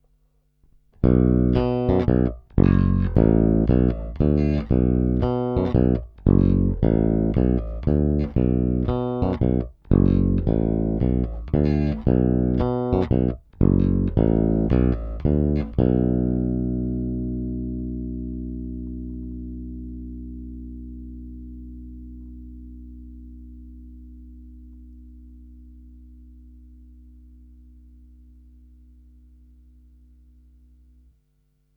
Není-li řečeno jinak, následující nahrávky jsou provedeny rovnou do zvukové karty a jen normalizovány, basy a výšky na nástroji nastavené skoro naplno.
Oba snímače